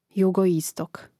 Rastavljanje na slogove: ju-go-i-stok